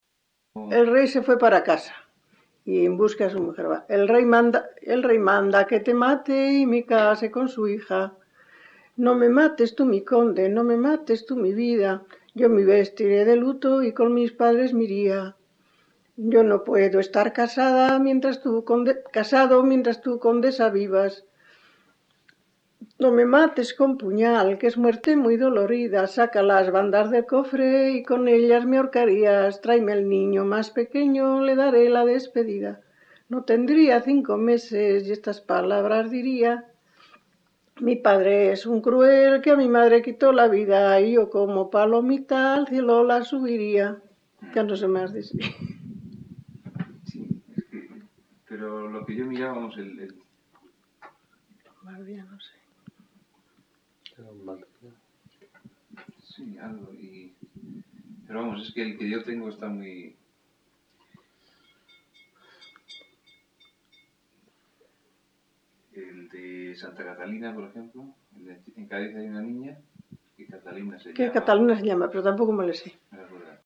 Grabación realizada en La Overuela (Valladolid), en 1977.
Canciones populares Icono con lupa